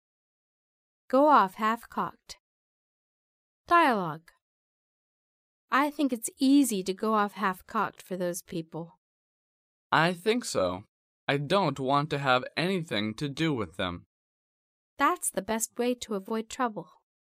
第一， 迷你对话